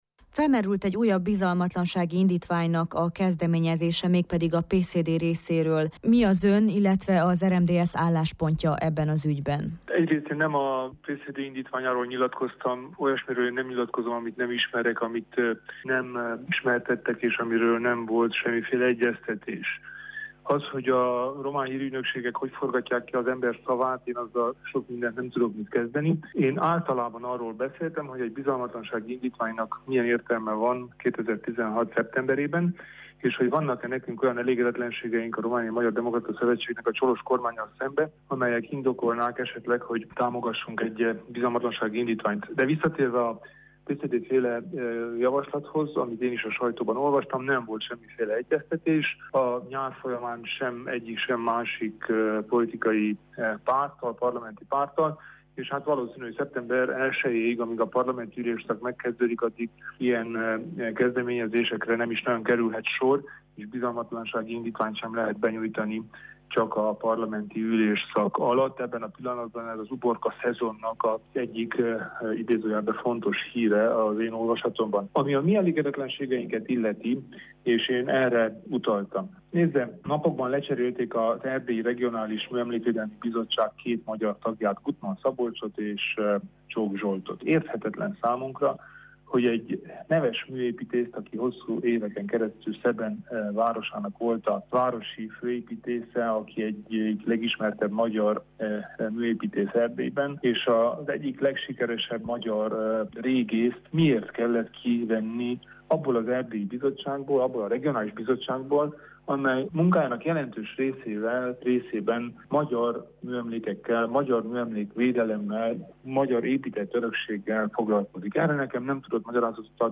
Kelemen Hunor rádiónknak nyilatkozva azt mondta: nem ismerik a Liviu Dragnea vezette alakulat érveit, és nem is keresték meg őt e tekintetben.